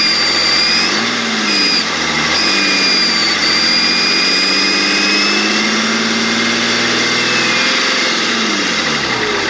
Symptom #4 Whining or Siren Noise (Bearing Failure) — A healthy turbo makes a smooth, almost quiet whistle. But when I hear a loud whining, grinding, or siren-like noise, I know something is wrong.
turbo-sound-2.mp3